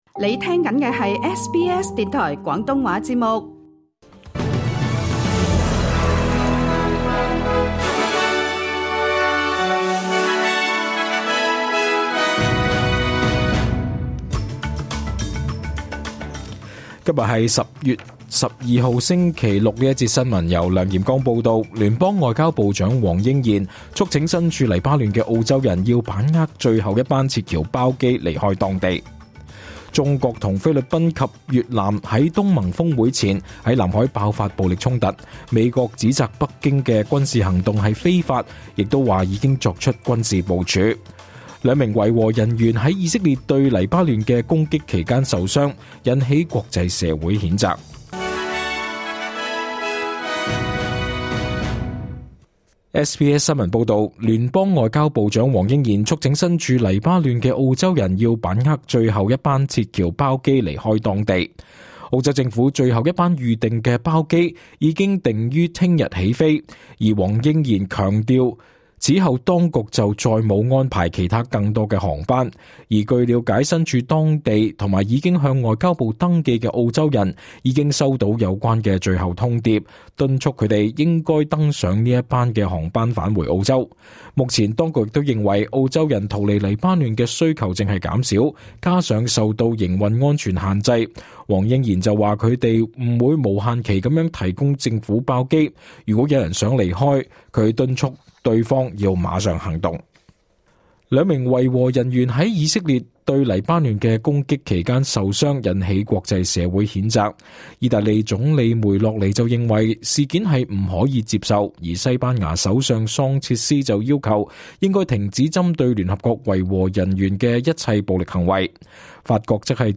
2024 年 10 月12 日 SBS 廣東話節目詳盡早晨新聞報道。